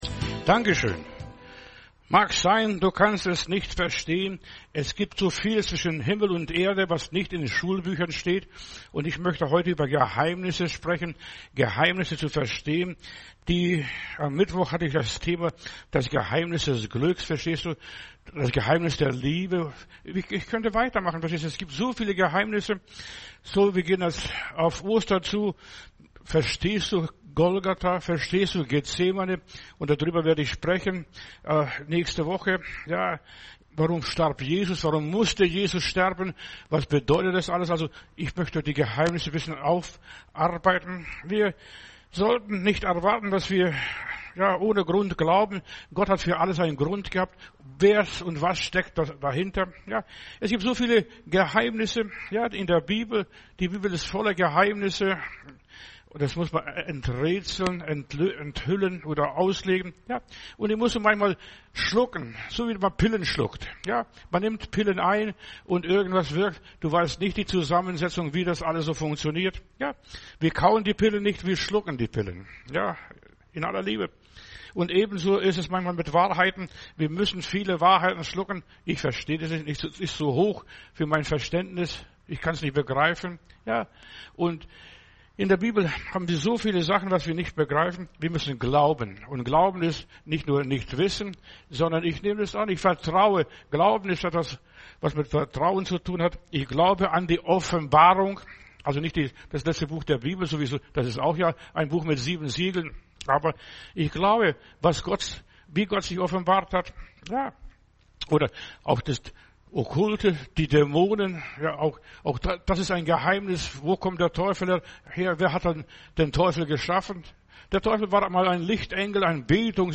Geheimnisse verstehen – Berliner Predigten